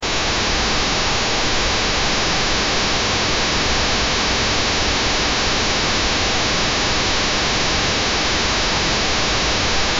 Subtle white noise effect such as gentle rain or fan sound fading in
subtle-white-noise-effect-v34t7wyw.wav